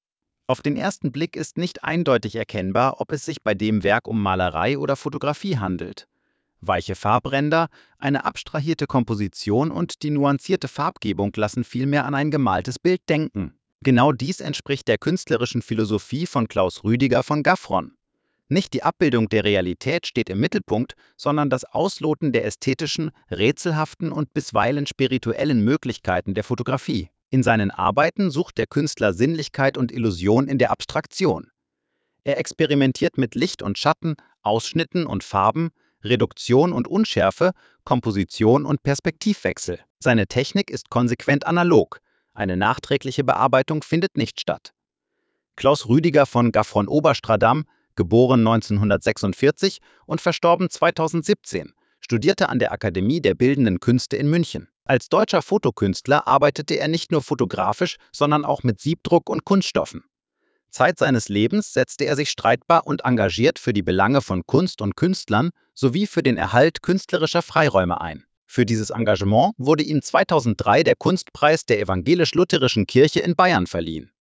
Audiostimme: KI generiert